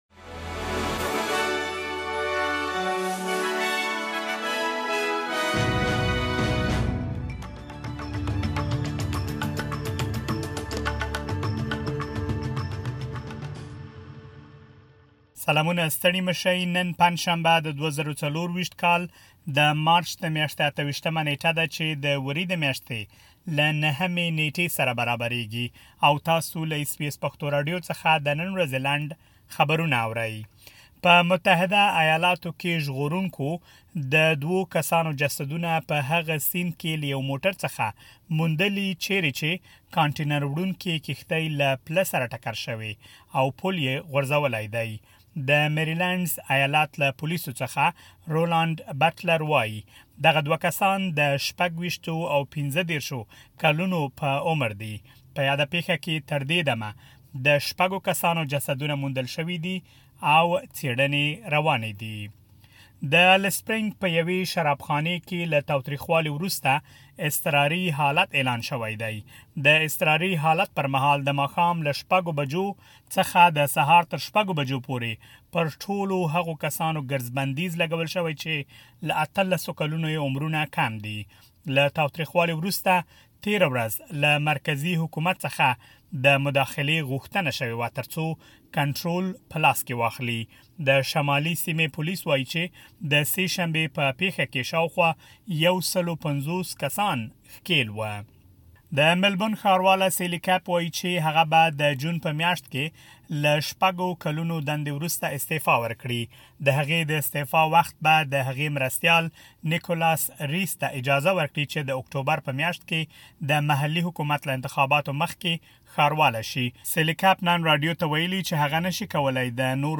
اس بي اس پښتو راډیو د نن ورځې لنډ خبرونه دلته واورئ.